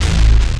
bseTTE52008hardcore-A.wav